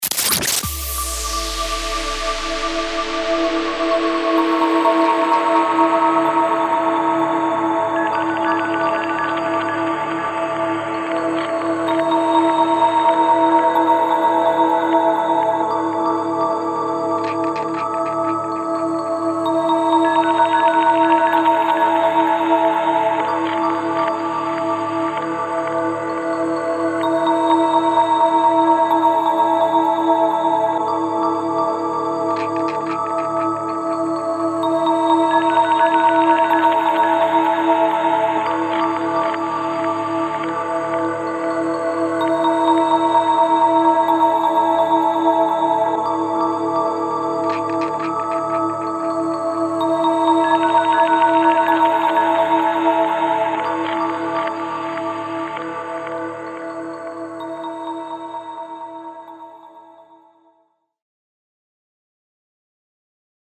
DRONE-032-PHONE-DRONE-128BPM-Dm